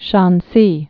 (shänsē)